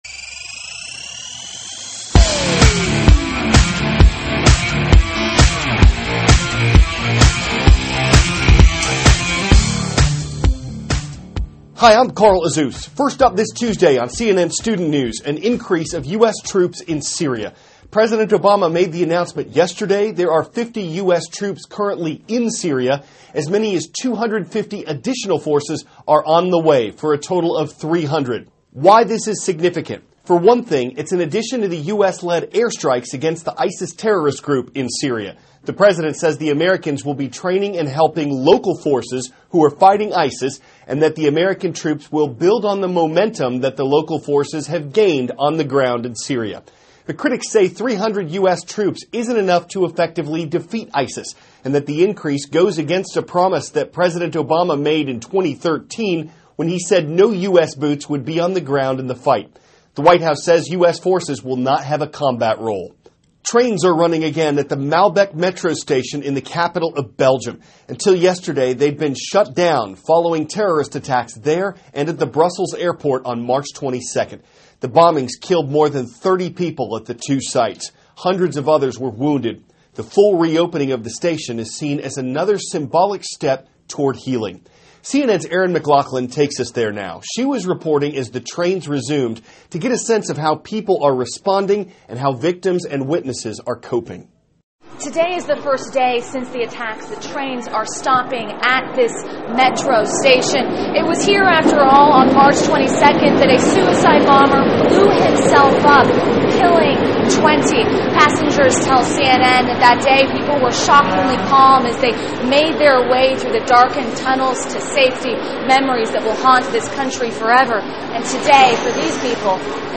(cnn Student News) -- April 26, 2016 More U.S. Troops in Syria; Reopening of a Belgian Train Station; A Dome for Chernobyl; Restrains on a Mars Mission. Aired 4-4:10a ET THIS IS A RUSH TRANSCRIPT.